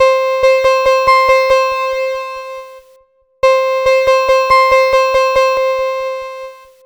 Cheese Lix Synth 140-C.wav